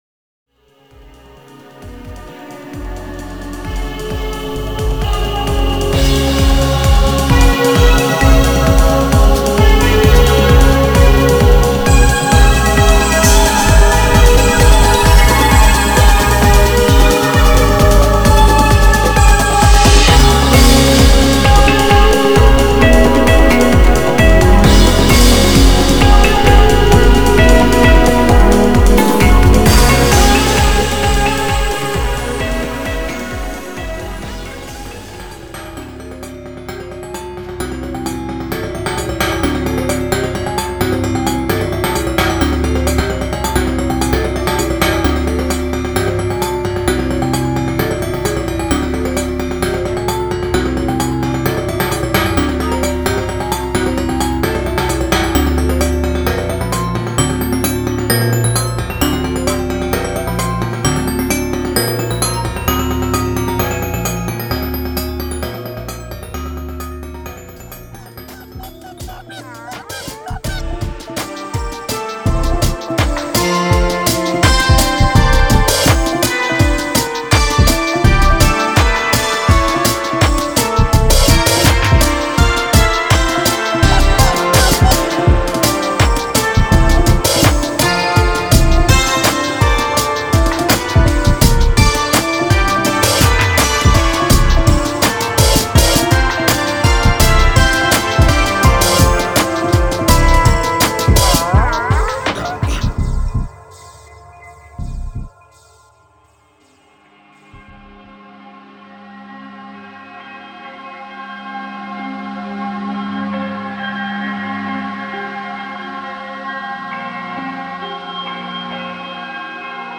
聖地に響くは、まつろわぬ拍の調べ